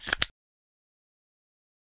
Descarga de Sonidos mp3 Gratis: abrochadora.
pop-clip.mp3